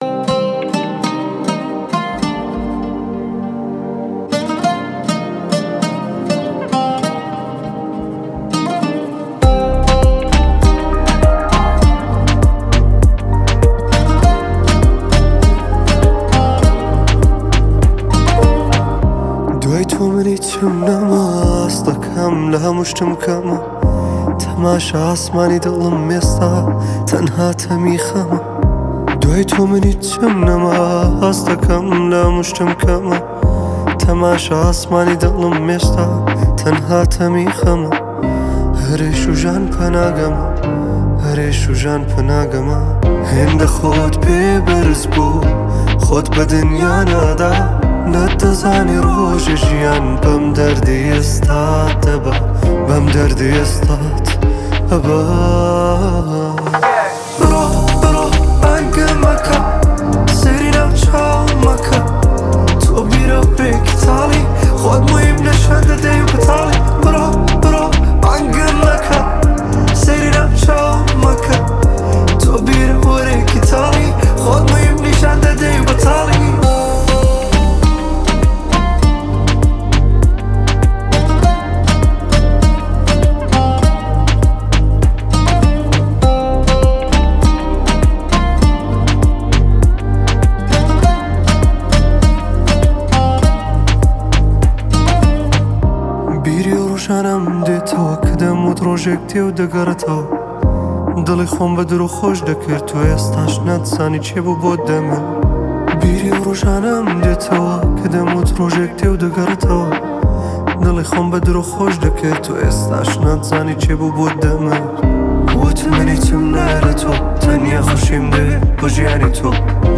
تک اهنگ کردی